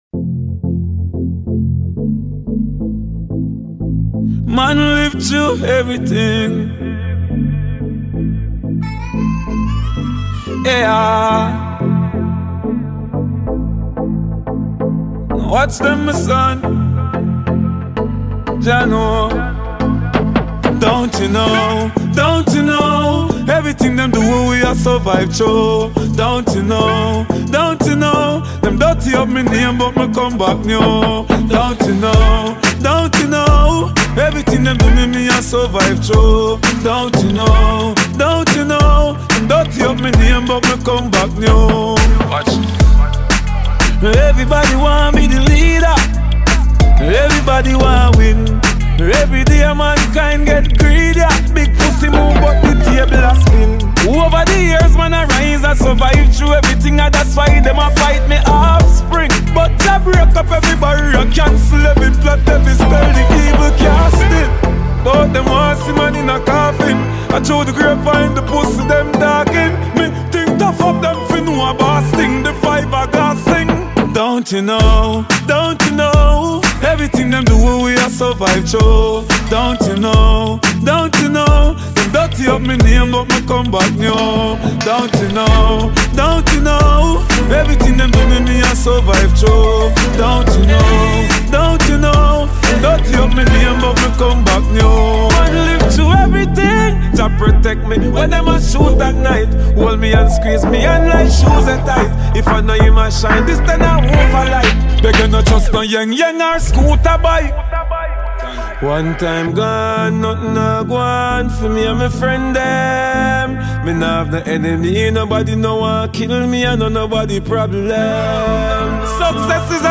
Dancehall/HiphopMusic
Lyrically blessed Jamaican dancehall musician